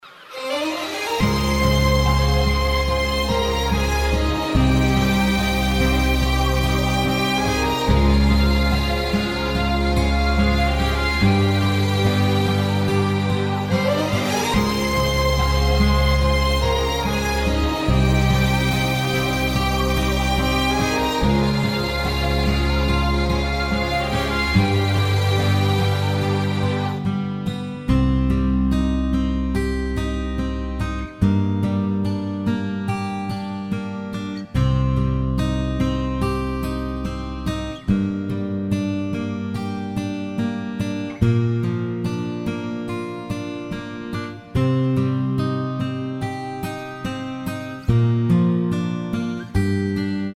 לשמיעת גירסה מלאה של הפלייבק  לחץ כאן